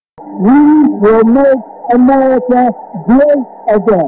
任何人，只要拥有一台笔记本，和价值不到1000美元的望远镜 + 光电传感器，就能实时监听25米开外房间里的声音。
信号在经过带阻滤波器、正则化、降噪、均衡器这四步处理之后，可以以惊人的保真度重建房间内的声音。
比如，文章开头川普的演讲片段：
其二，在实验中，扬声器的音量被开到了最大。